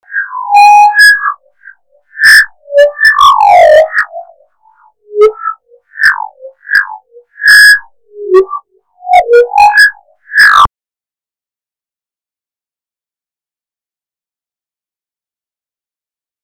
Packe euch mal ein Soundfile dazu rein (Achtung! leise hören, sehr schrill).
Es kommen einfach nur schrille Sounds beim betätigen des Pedals in die DAW an.